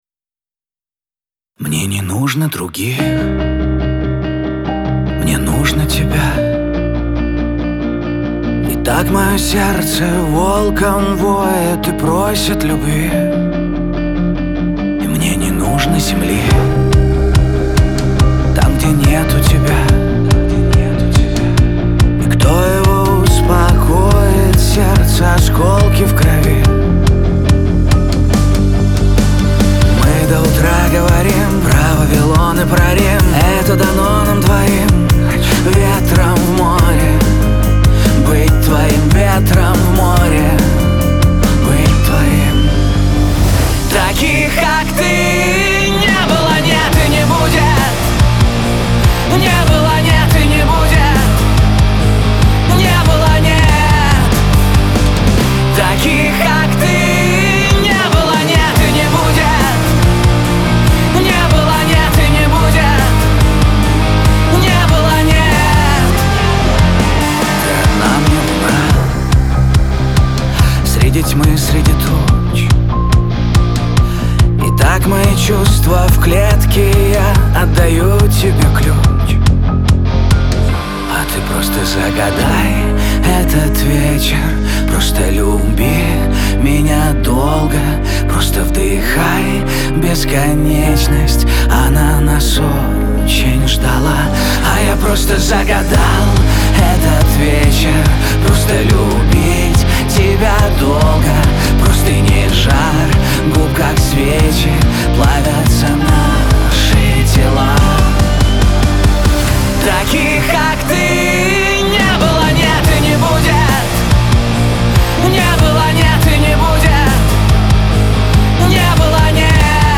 эстрада , pop
Лирика